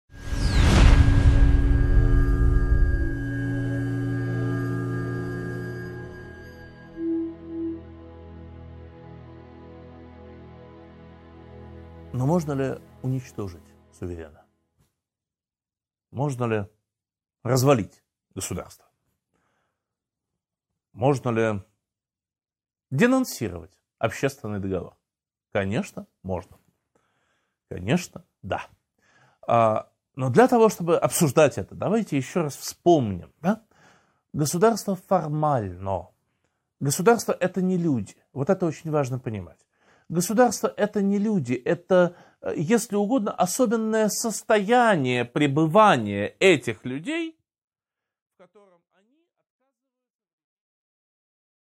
Аудиокнига 12.8 Право на восстание | Библиотека аудиокниг